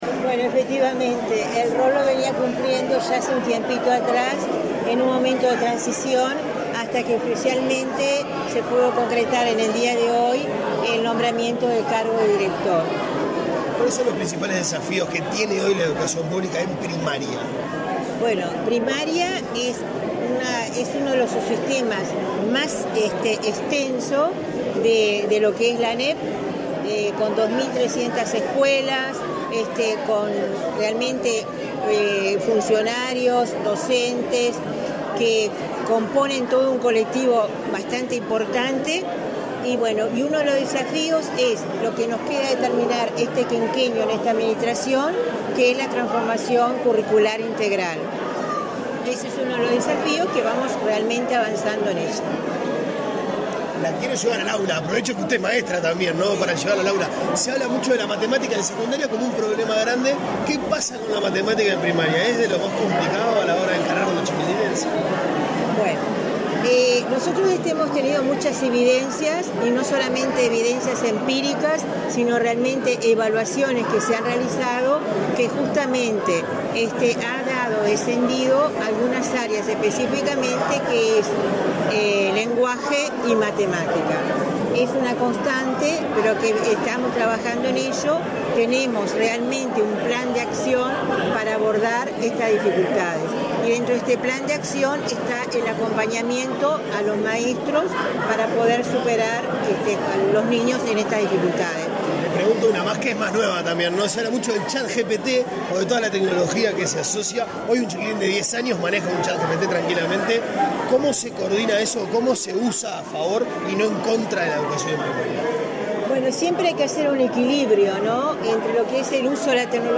Declaraciones a la prensa de la nueva directora general de Educación Inicial y Primaria, Olga de las Heras
Declaraciones a la prensa de la nueva directora general de Educación Inicial y Primaria, Olga de las Heras 25/07/2023 Compartir Facebook X Copiar enlace WhatsApp LinkedIn Olga de las Heras asumió como directora general de Educación Inicial y Primaria, este 25 de julio. Tras el evento, la jerarca realizó declaraciones a la prensa.